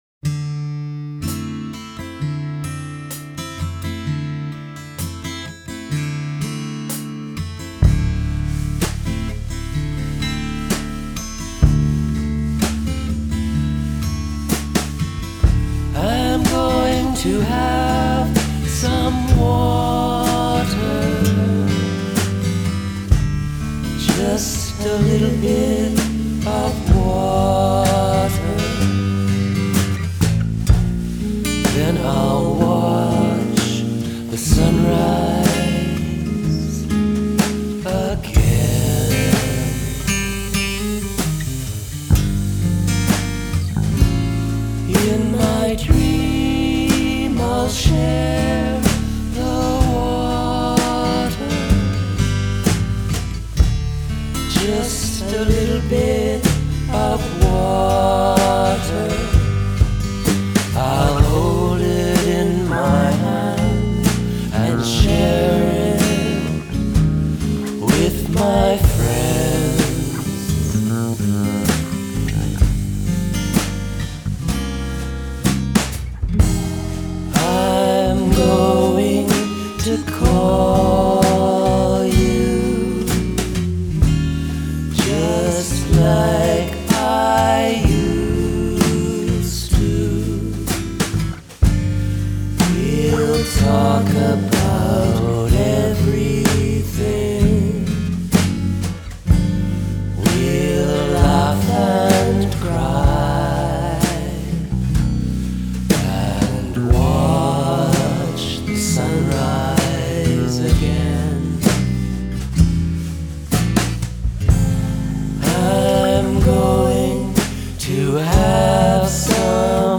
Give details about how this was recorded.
Recorded in Winnipeg in February 2023